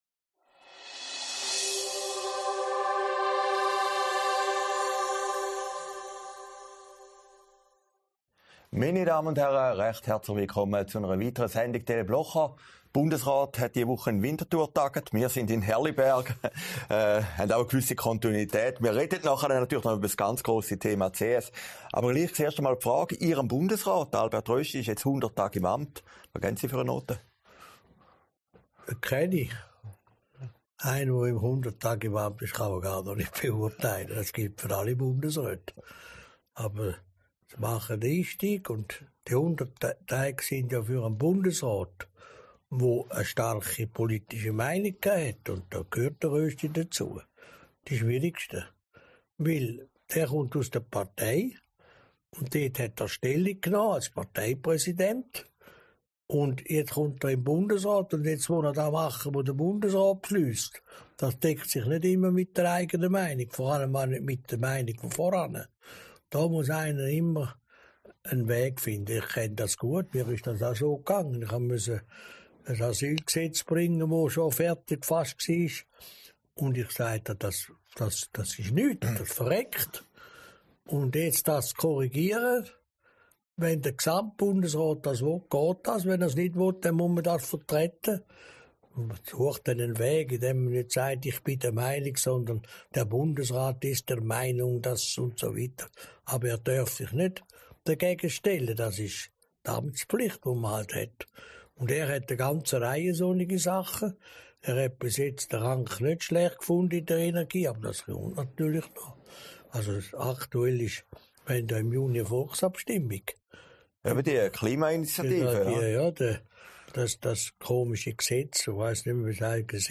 Sendung vom 28. April 2023, aufgezeichnet in Herrliberg